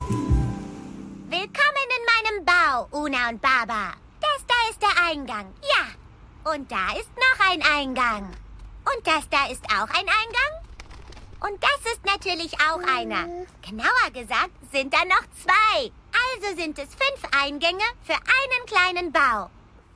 Animación
Micrófono: Neumann TLM 103